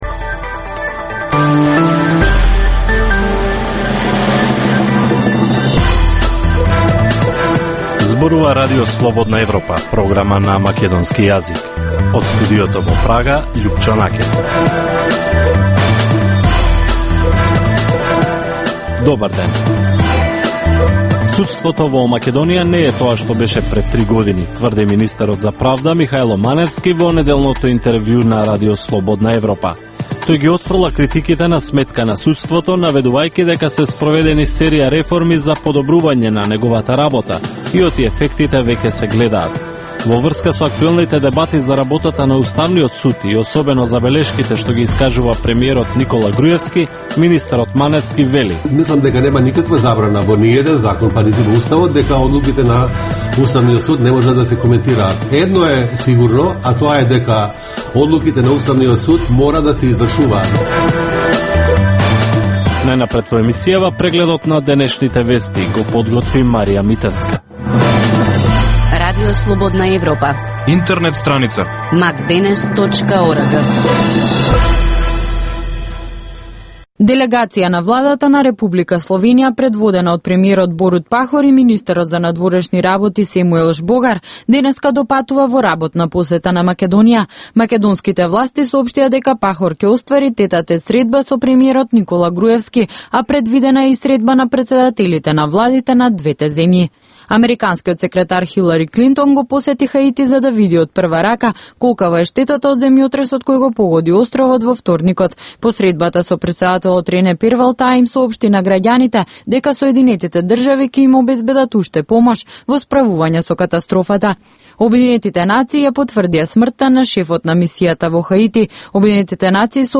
Информативна емисија, секој ден од Студиото во Прага.